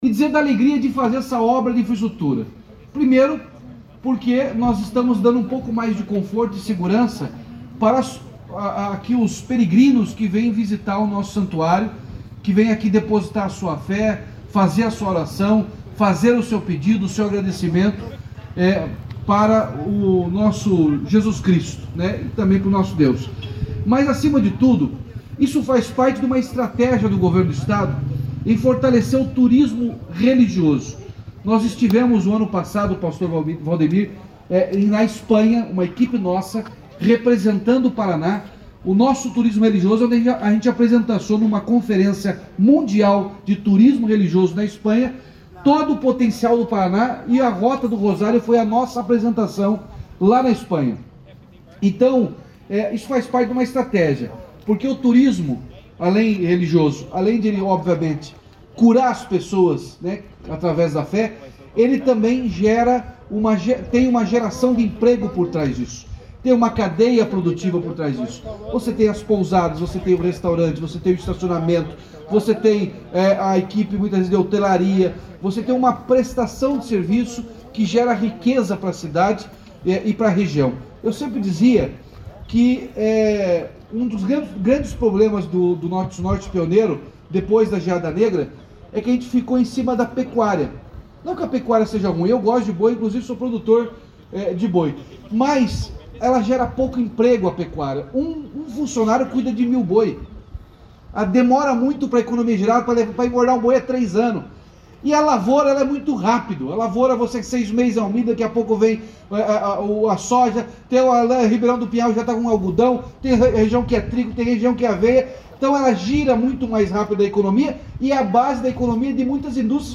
Sonora do governador Ratinho Junior sobre a inauguração da nova pavimentação do Santuário de Joaquim Távora